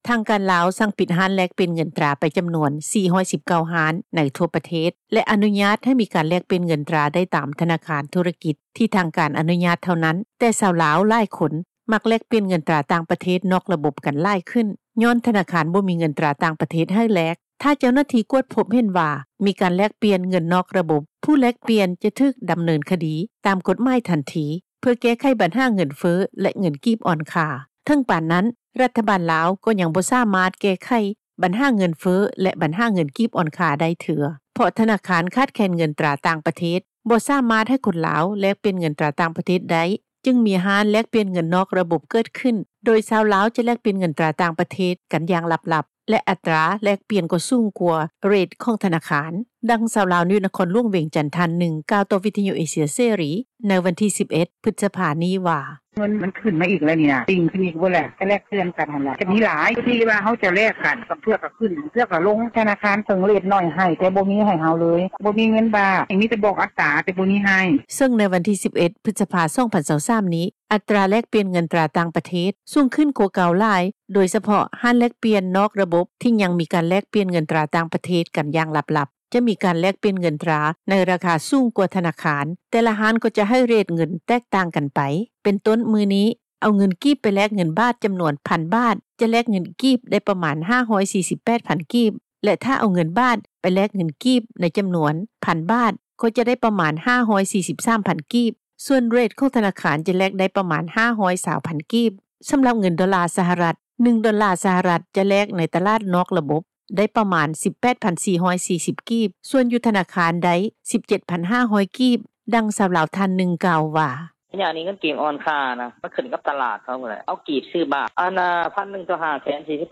ດັ່ງຊາວລາວ ໃນນະຄອນຫຼວງວຽງຈັນ ທ່ານນຶ່ງ ກ່າວຕໍ່ ວິທຍຸ ເອເຊັຽ ເສຣີ ໃນວັນທີ 11 ພຶສພາ ນີ້ວ່າ: